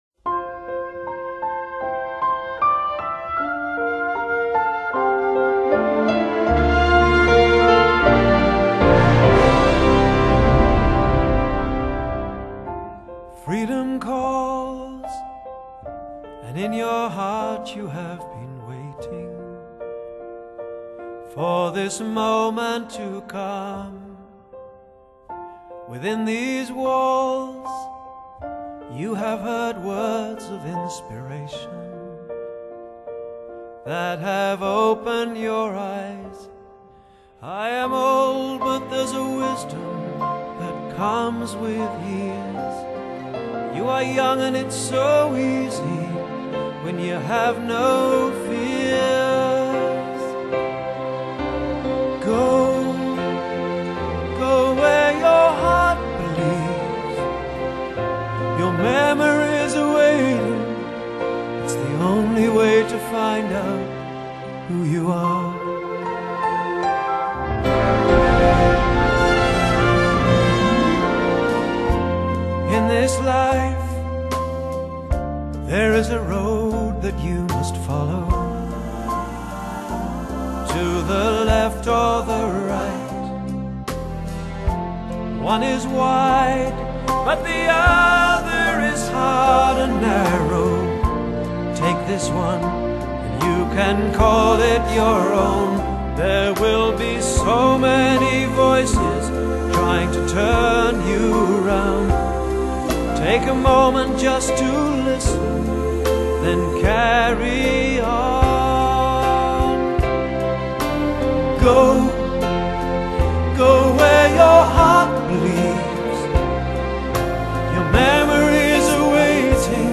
Pop/Rock | MP3 320 Kbps | 181 Mb | 2010 | BOOKLET